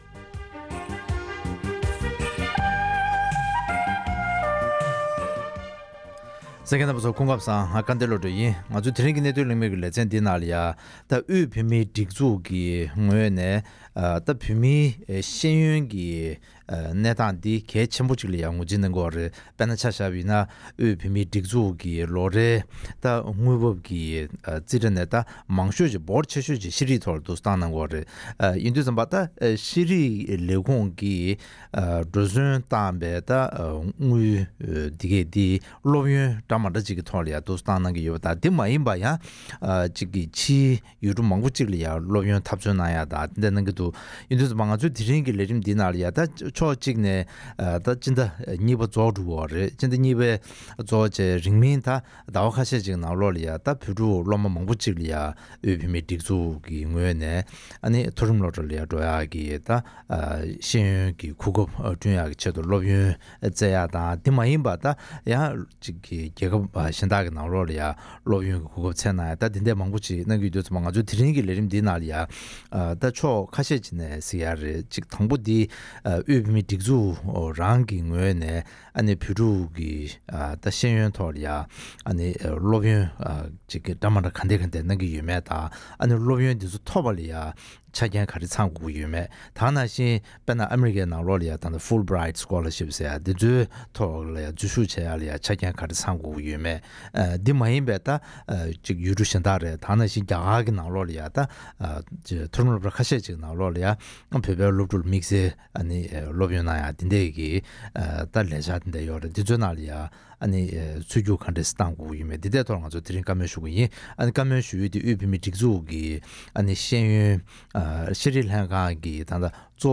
བོད་མིའི་སྒྲིག་འཛུགས་ཀྱི་ངོས་ནས་བོད་ཕྲུག་རྣམས་ལ་སློབ་ཡོན་འདྲ་མིན་གནང་ཕྱོགས་དང་སློབ་ཕྲུག་གི་ངོས་ནས་ཆ་རྐྱེན་ག་རེ་ཚང་དགོས་ཀྱི་ཡོད་མེད་ཐད་གླེང་མོལ།